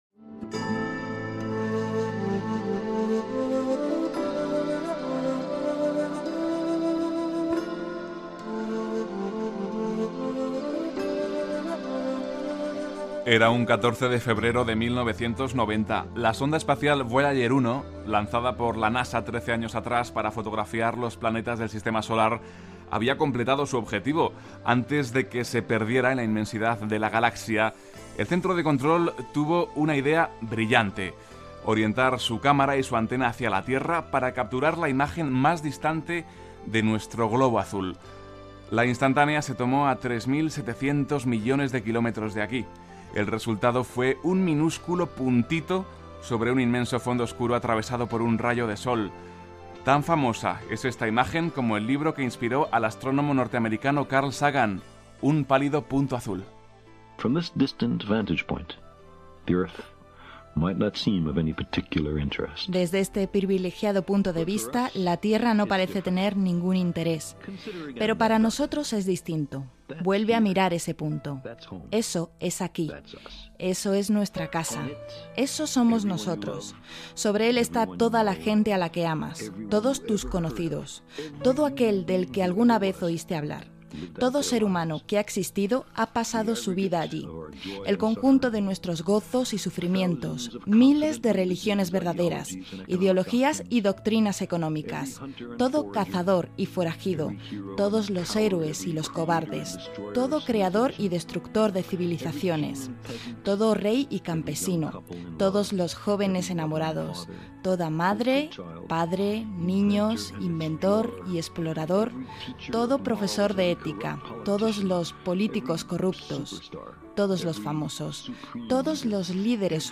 La imatge de la terra des de l'espai, presentació i espai dedicat a Istanbul. Careta del programa, dades històriques, itinerari que es farà i inici del viatge sonor